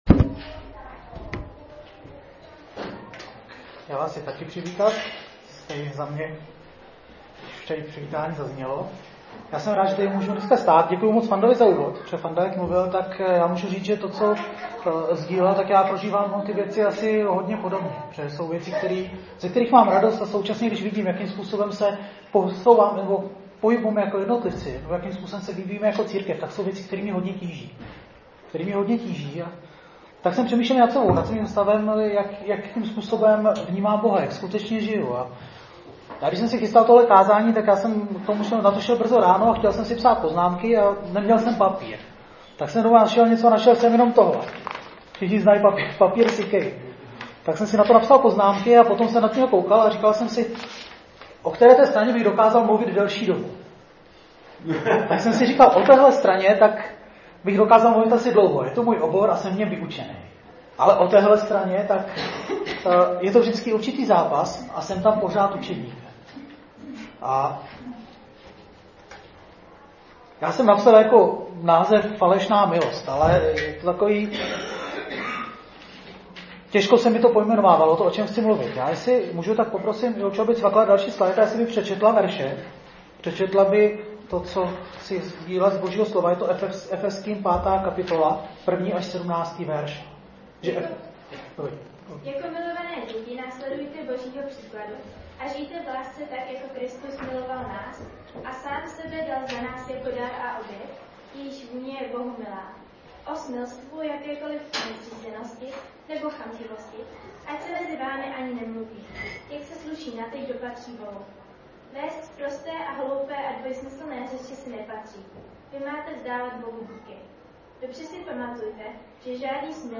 Kázání - archiv - 2018